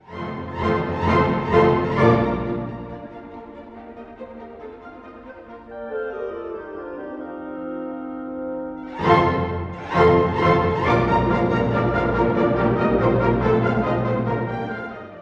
↑古い録音のため聴きづらいかもしれません！（以下同様）
Adagio molto – Allegro con brio
～きわめてゆるやかに　-　快速に、活気をもって～
何も起こらない、空虚を感じます。
しかし、途中から活力を帯びていき、ほどなく明るい主題に移り変わります。
第一主題、第二主題ともに分散和音で構成されています。実は序奏も分散和音ですね。
こうした統一性があるためか、前向きなのに格調高さも感じられます。